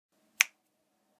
دانلود صدای بشکن 3 از ساعد نیوز با لینک مستقیم و کیفیت بالا
جلوه های صوتی
برچسب: دانلود آهنگ های افکت صوتی انسان و موجودات زنده دانلود آلبوم صدای بشکن زدن از افکت صوتی انسان و موجودات زنده